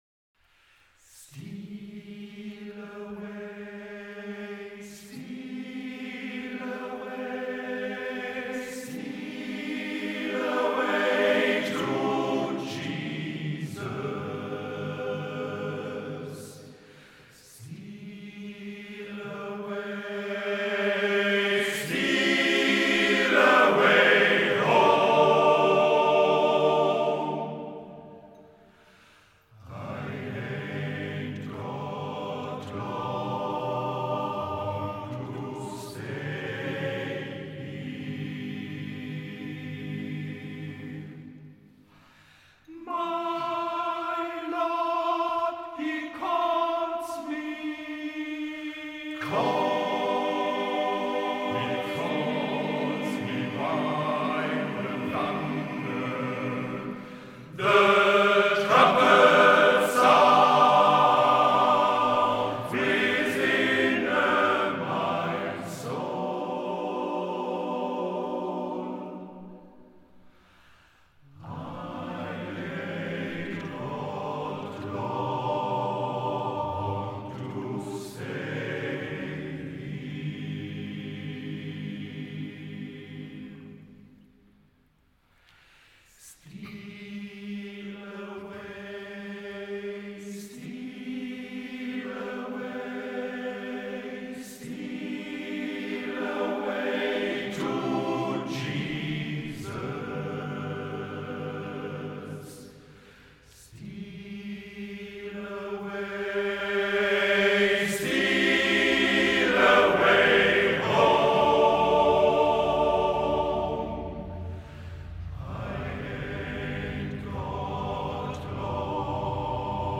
Unsere Wettbewerbsstücke vom Männerchorwettbewerb 2022 in Horbach:
Steal Away (Negro Spiritual in einem Arrangement von Marshall Bartholomew)